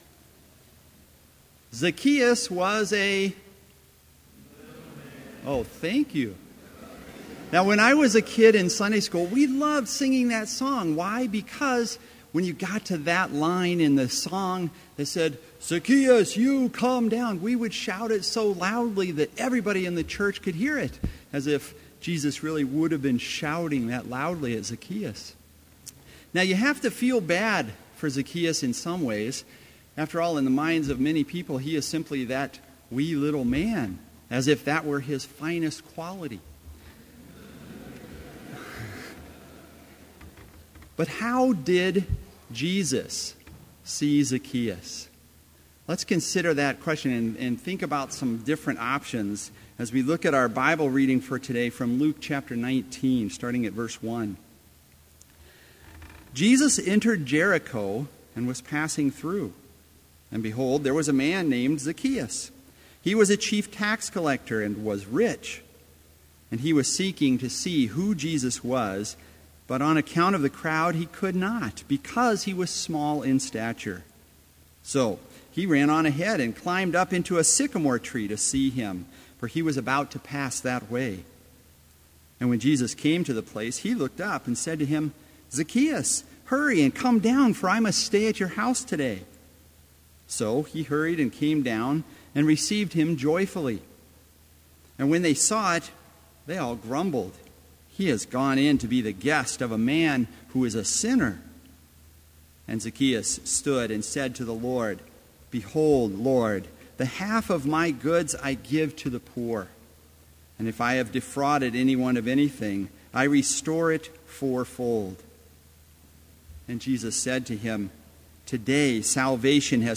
Complete Service
• Children's Choir: "Come, See"
This Chapel Service was held in Trinity Chapel at Bethany Lutheran College on Thursday, December 10, 2015, at 10 a.m. Page and hymn numbers are from the Evangelical Lutheran Hymnary.